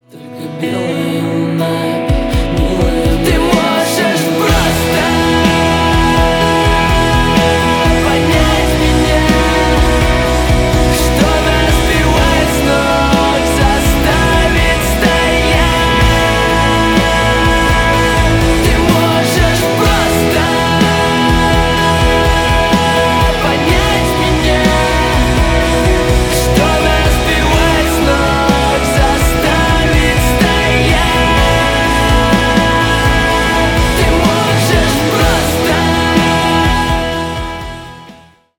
• Качество: 320 kbps, Stereo
Рок Металл